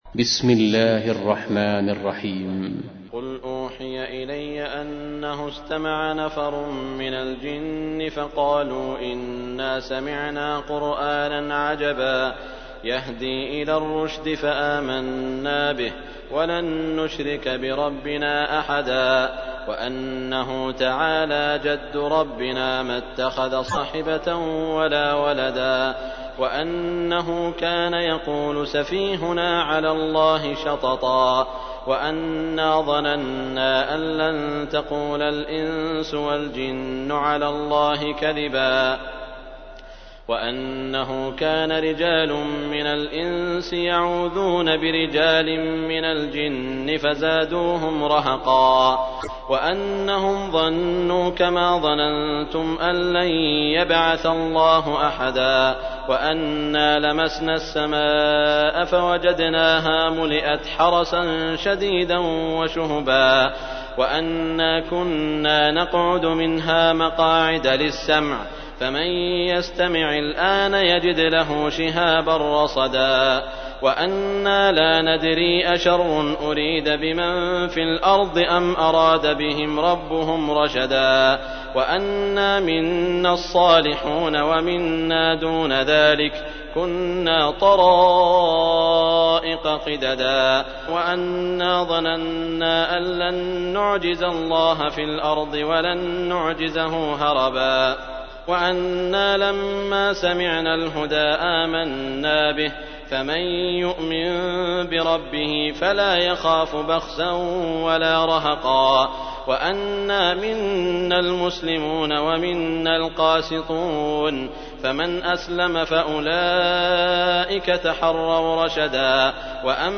تحميل : 72. سورة الجن / القارئ سعود الشريم / القرآن الكريم / موقع يا حسين